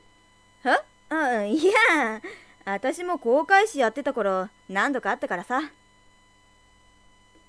ＳＡＭＰＬＥ　ＶＯＩＣＥ
イメージではちょっと低めの声。勢いがあるといいかな。